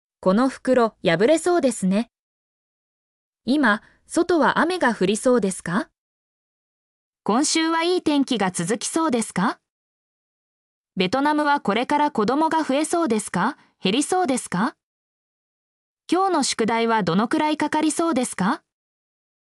mp3-output-ttsfreedotcom-5_tDtlrU2m.mp3